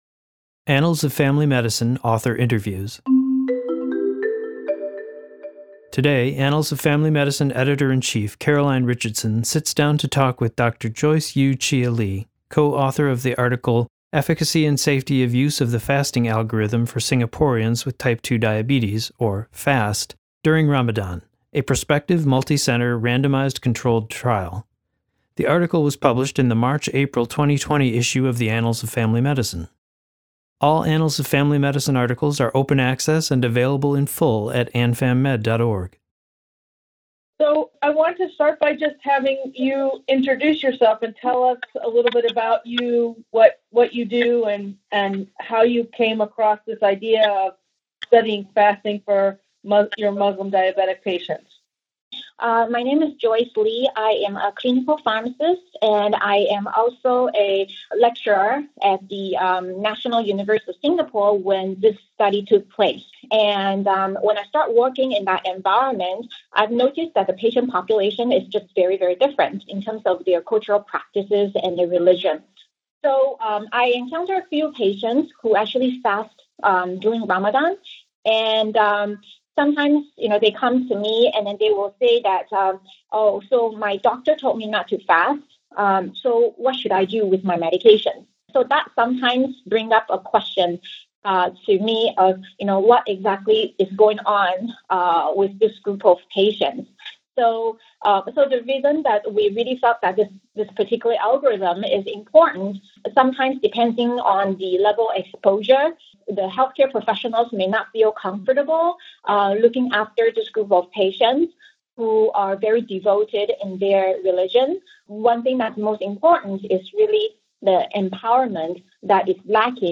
Author audio interview